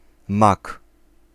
Ääntäminen
Ääntäminen France: IPA: [pa.vo] Haettu sana löytyi näillä lähdekielillä: ranska Käännös Ääninäyte Substantiivit 1. mak {m} Suku: m .